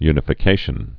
(ynə-fĭ-kāshən)